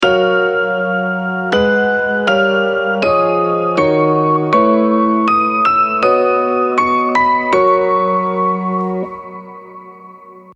Neosoul/R&B